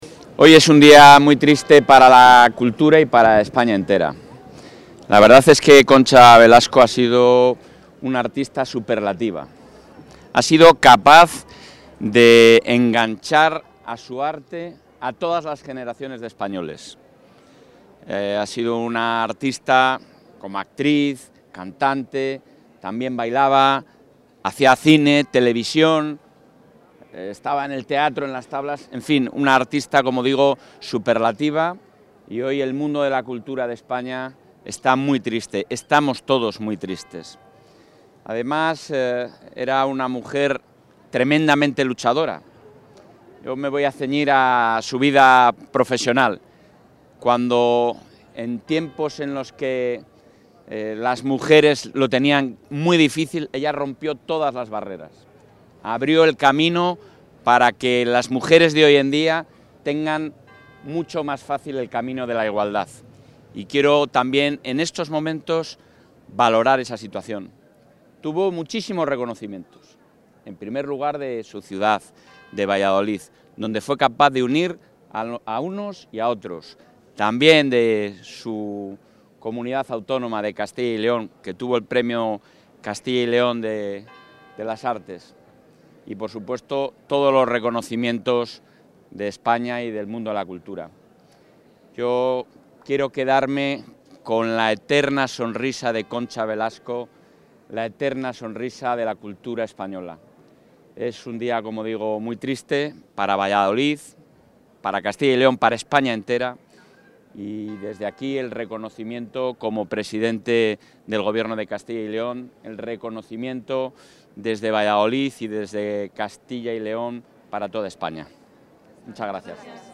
Declaraciones del presidente de la Junta.
El presidente de la Junta de Castilla y León, Alfonso Fernández Mañueco, ha asistido hoy a la misa funeral por Concha Velasco en la Catedral de Valladolid.